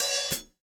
Index of /musicradar/Kit 3 - Acoustic
CyCdh_K3OpHat-01.wav